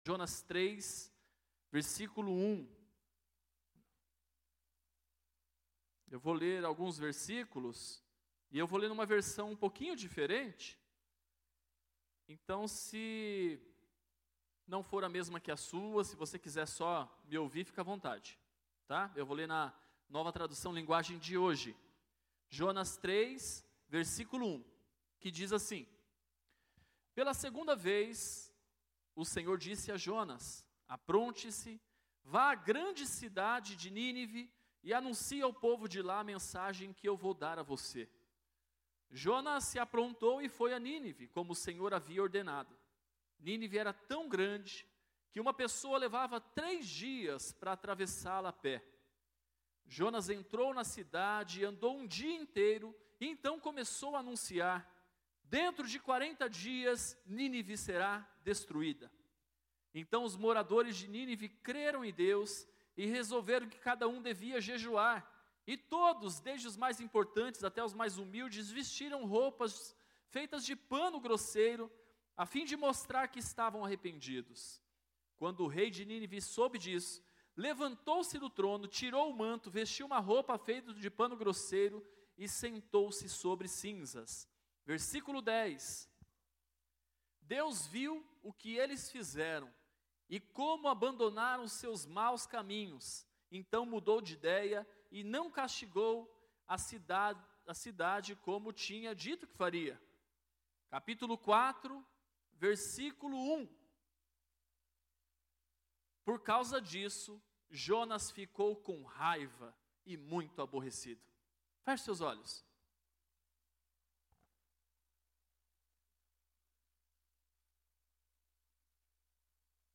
Mensagem do dia 21 de Julho de 2019 para o culto de batismo.